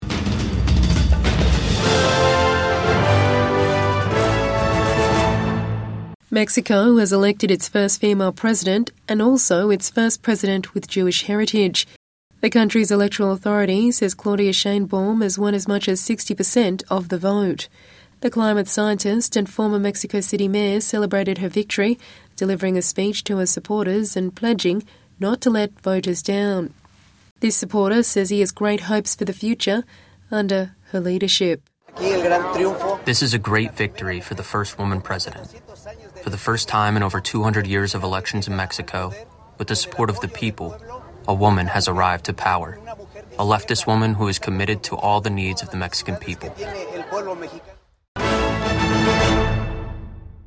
Mexico's first female president delivers her victory speech